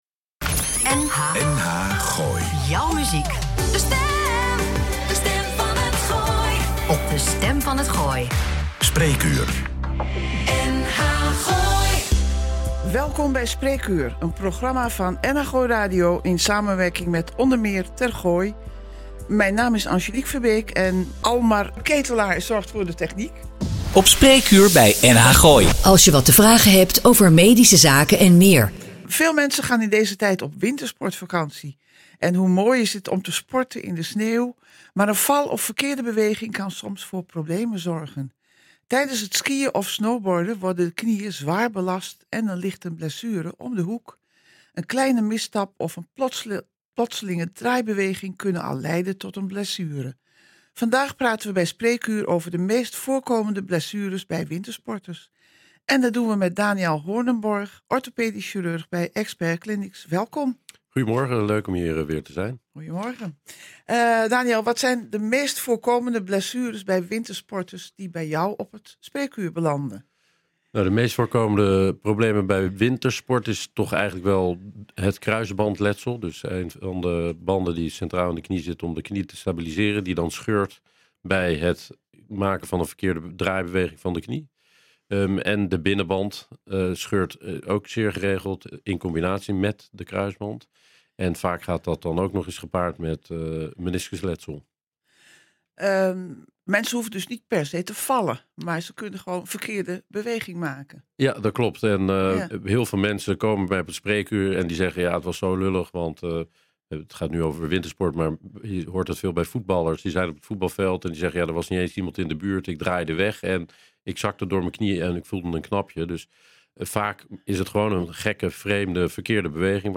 NH Gooi Spreekuur - Knieblessures bij wintersport, in gesprek met de specialist
nh-gooi-spreekuur-knieblessures-wintersport-gesprek-specialist.mp3